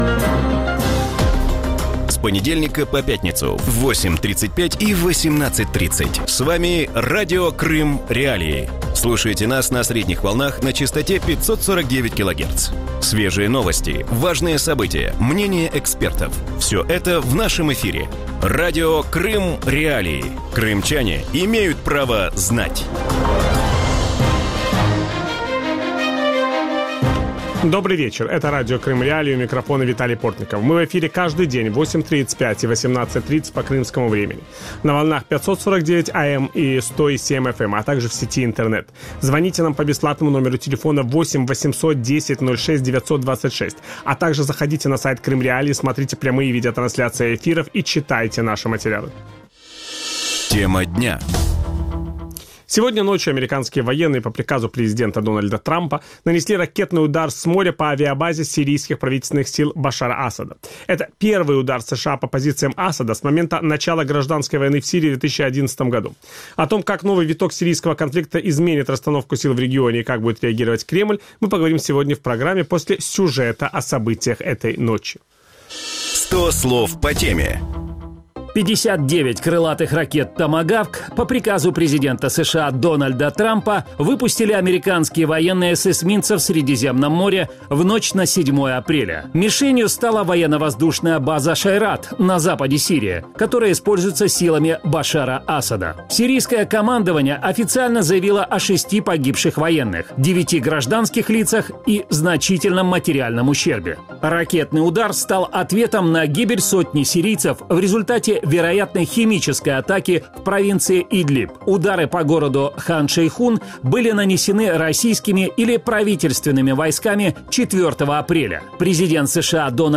В вечернем эфире Радио Крым.Реалии обсуждают ракетный удар американских военных по правительственным силам сирийской армии. Как первый удар США по правительственным силам Башара Асада в Сирии изменит конфигурацию сил в регионе?
Ведущий – Виталий Портников.